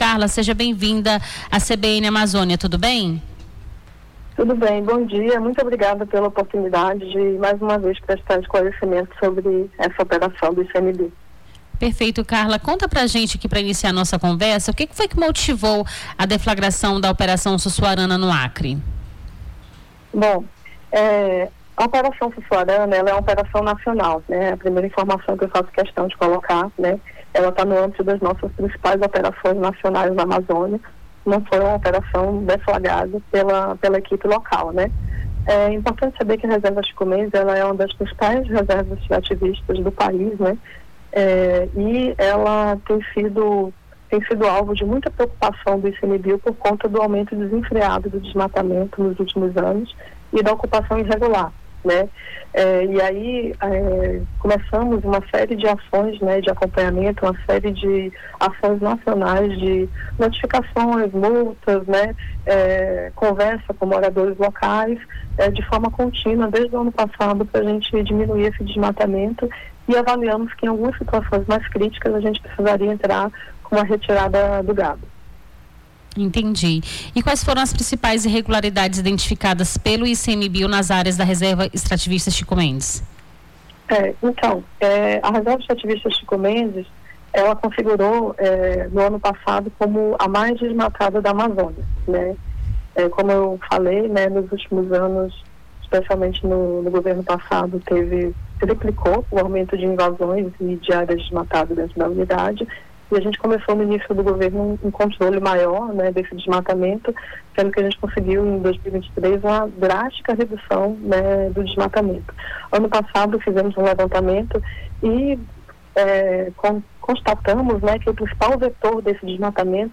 Nome do Artista - CENSURA - ENTREVISTA (OPERAÇÃO SUSSUARANA) 24-06-25.mp3